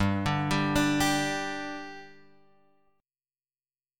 G5 chord {3 5 5 x 3 3} chord